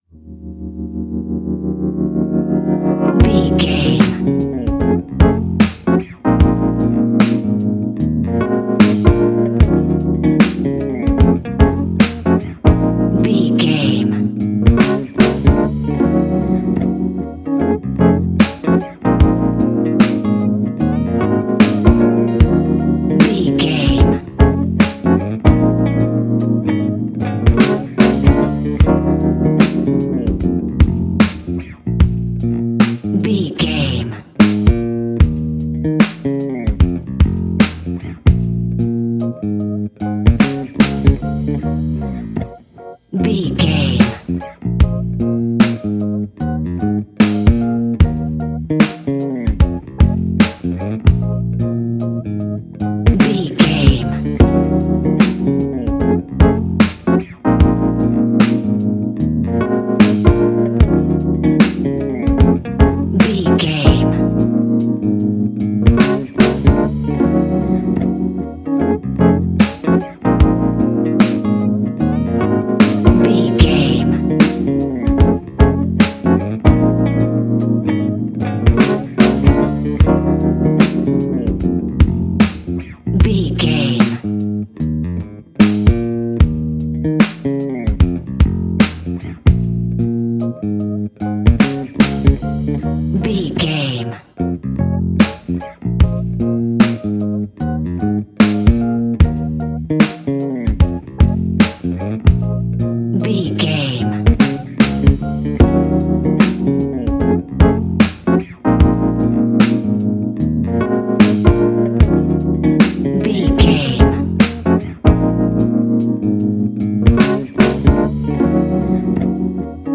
Fast paced
In-crescendo
Uplifting
Ionian/Major
D♯
instrumentals